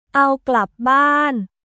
アオ・グラップ・バーン